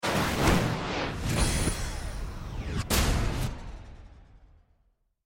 На этой странице собраны звуки, связанные с отключением электричества: резкие щелчки рубильников, затихающее гудение техники, тревожные перебои напряжения.
Звук ломающегося и отключающегося генератора электричества